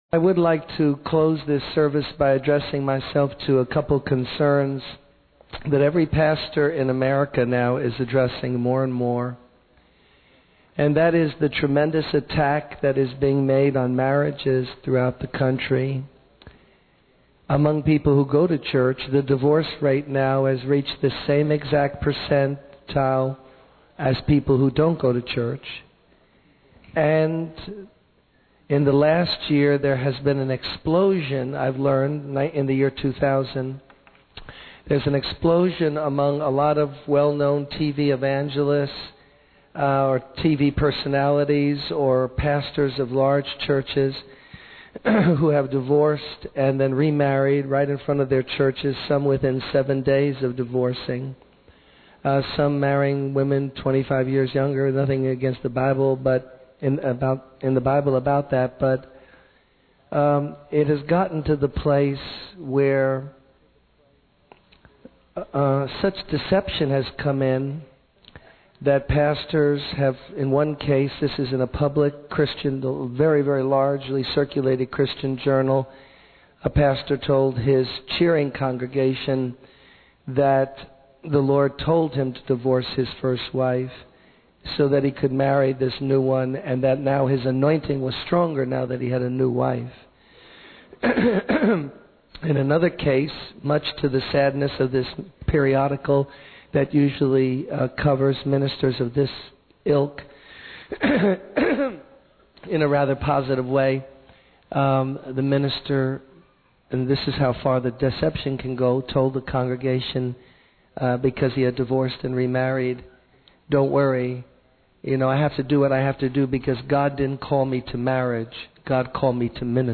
In this sermon, the speaker starts by sharing a personal anecdote about watching a nature channel and being disturbed by the aggressive behavior of wild dogs in South Africa. He then transitions to discussing the dangers of bitterness and isolation in our spiritual lives, emphasizing the importance of acting in a Christian way and avoiding conflicts that can lead to negative consequences. The speaker also highlights the significance of humility and self-control in relationships, particularly in marriage.